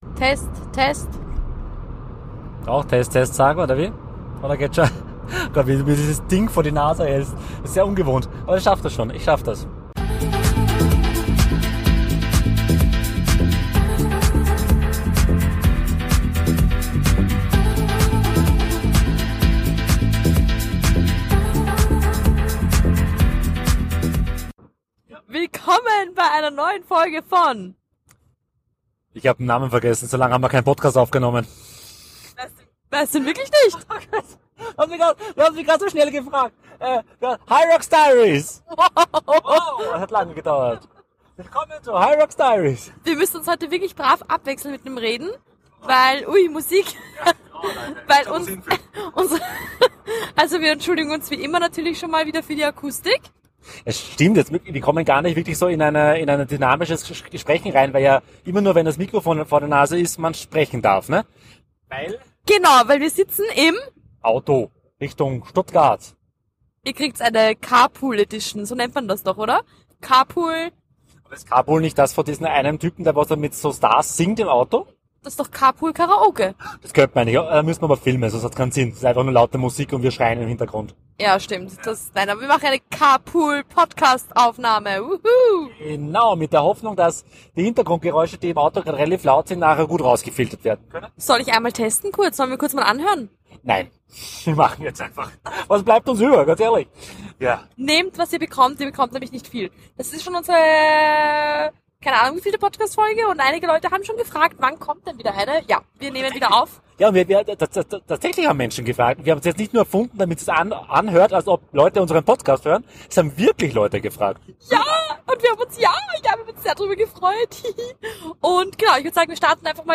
In dieser Folge nehmt ihr Platz auf dem Beifahrersitz wir sind unterwegs nach Stuttgart! Während der Autofahrt plaudern wir über all die Dinge, die seit der letzten Folge passiert sind.